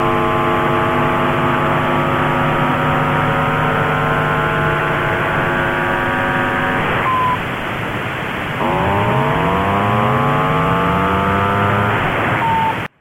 业余无线电 " BPSK63 THE QUICK BROWN FOX ...
描述：BPSK63是业余无线电台使用的一种调制方式。
标签： 火腿聊天 teletyping 通信 调制 BPSK63 火腿无线电 业余无线电
声道立体声